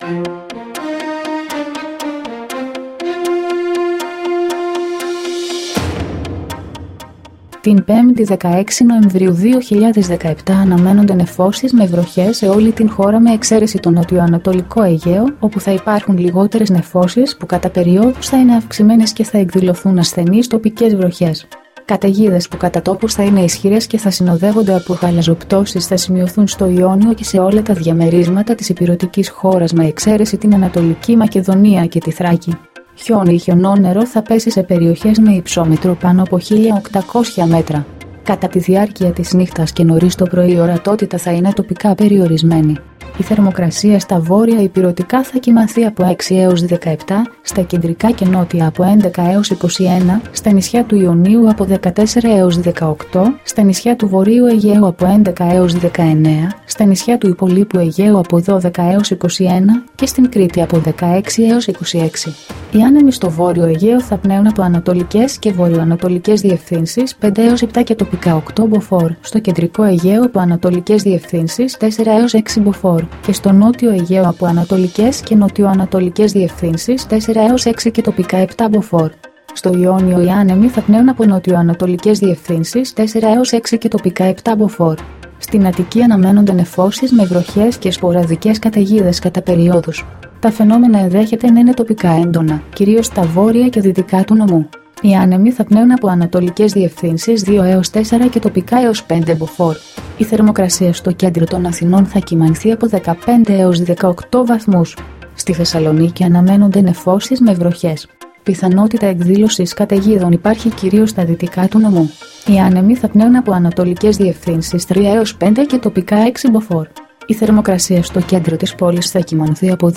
dailyforecast-9.mp3